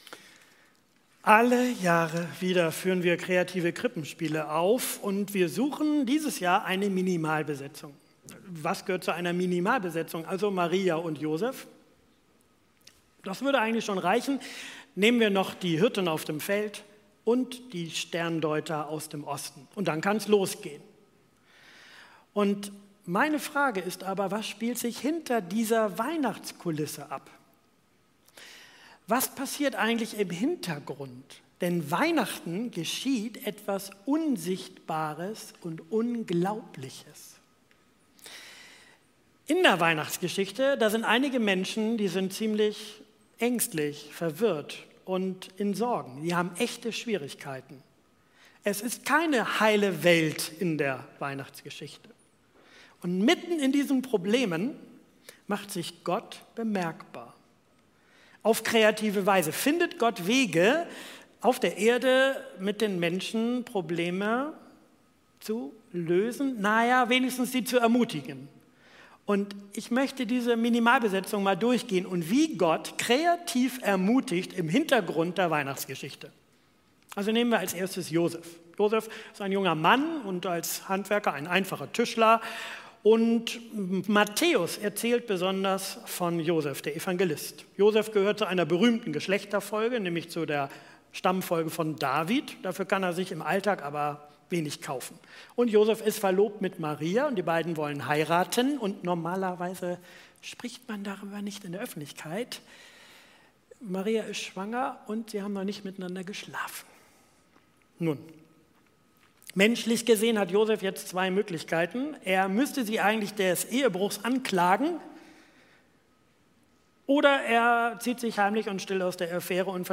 Predigttext: Matthäus 1,19-23; Lukas 1,26-38; Lukas 2,8-14